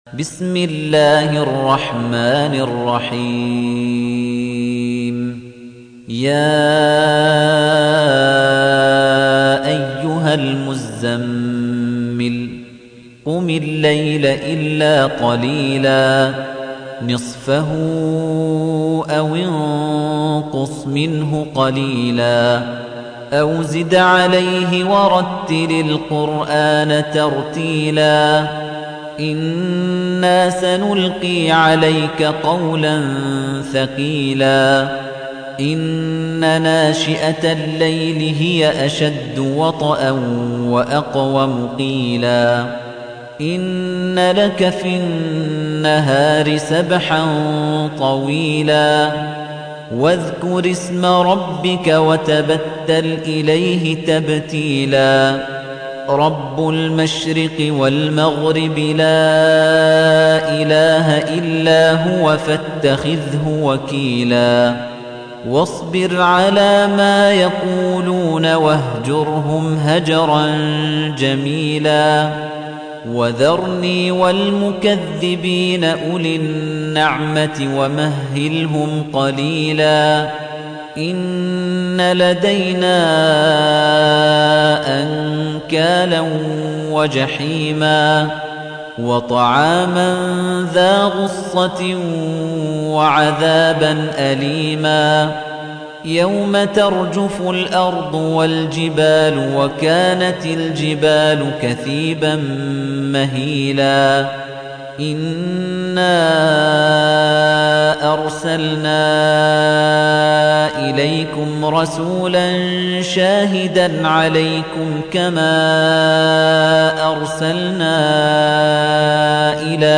تحميل : 73. سورة المزمل / القارئ خليفة الطنيجي / القرآن الكريم / موقع يا حسين